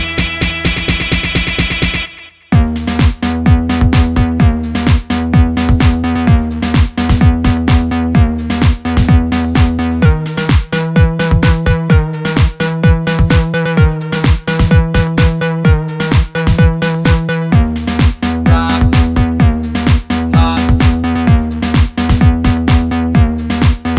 no DJ R'n'B / Hip Hop 4:11 Buy £1.50